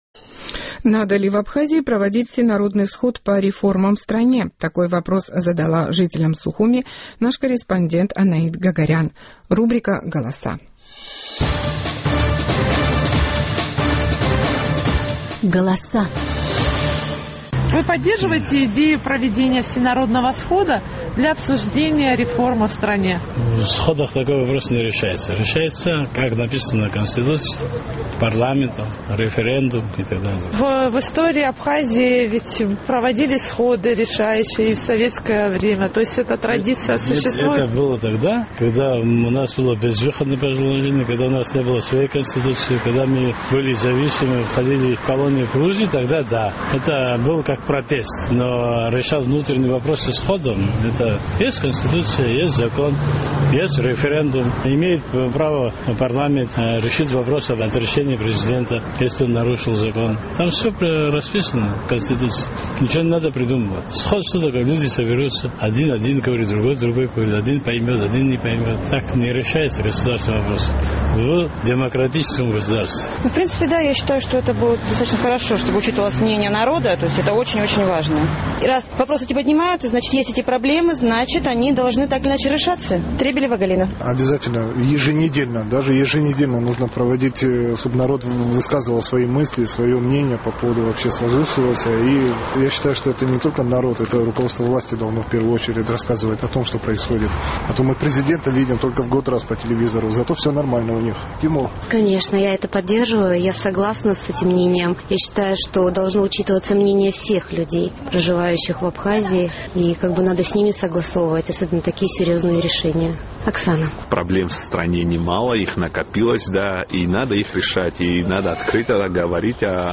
Наш сухумский корреспондент интересовался у жителей абхазской столицы, поддерживают ли они идею проведения всенародного схода для обсуждения реформ в стране?